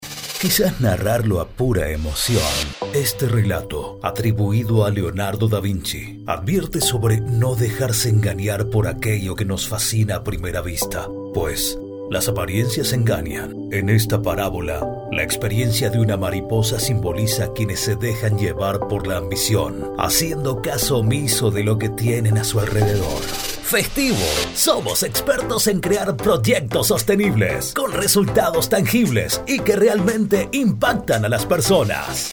spanisch Südamerika
Sprechprobe: Industrie (Muttersprache):